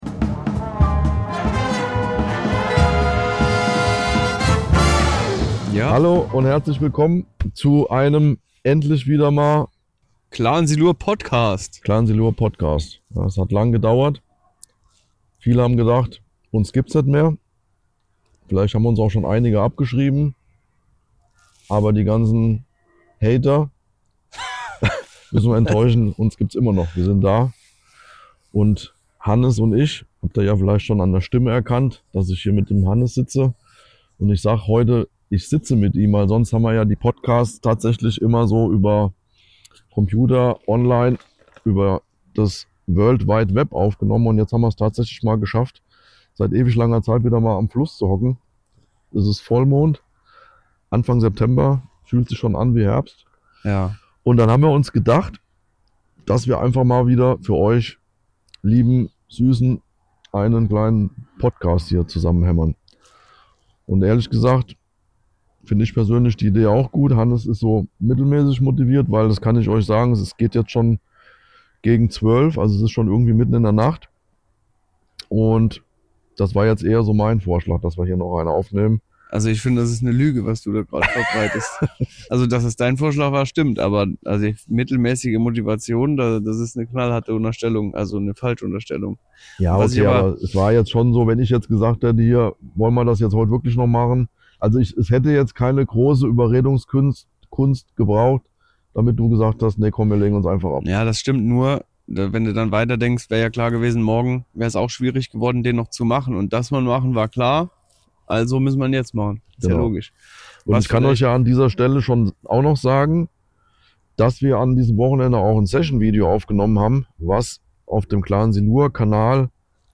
Dabei plaudern die beiden Freunde wirklich aus dem Nähkästchen. Wie war das Wallerangeln früher, wie ist es heute und wie könnte es morgen sein.
Darüberhinaus gibt es Dosenbier und den ein oder anderen Lacher!